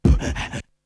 Beatbox 8.wav